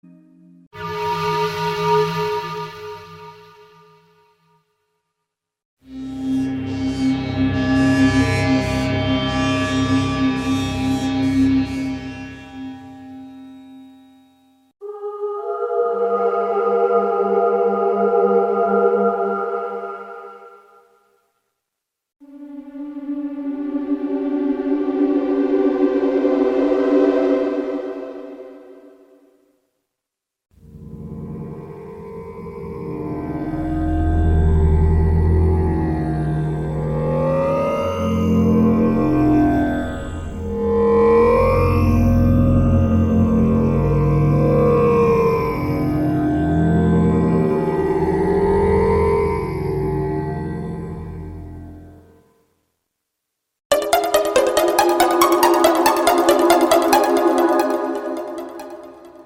効果音 ホラー